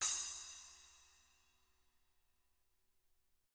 Index of /90_sSampleCDs/ILIO - Double Platinum Drums 1/CD4/Partition H/SPLASH CYMSD